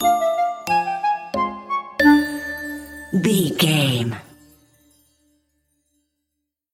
Aeolian/Minor
Slow
flute
oboe
piano
percussion
silly
circus
goofy
comical
cheerful
perky
Light hearted
quirky